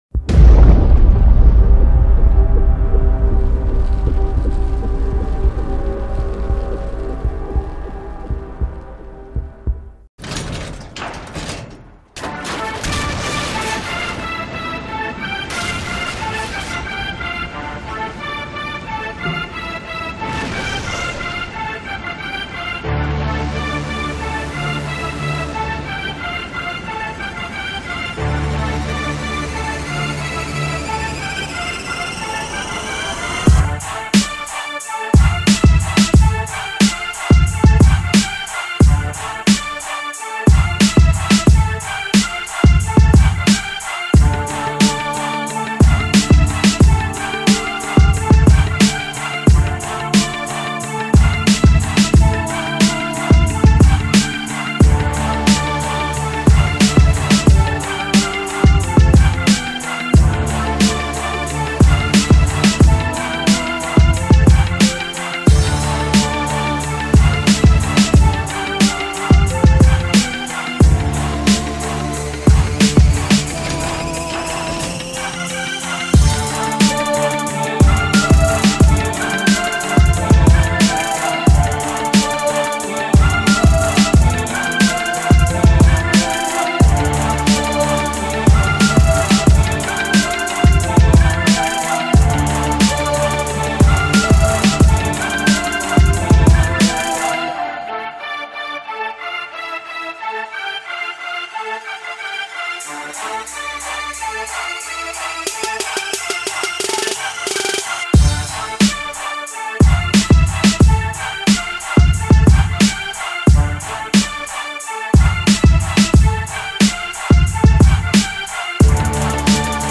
Hard_AGGRESSIVE_Gangsta_Rap_Beat_Instmental_.mp3